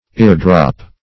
Eardrop \Ear"drop`\, n.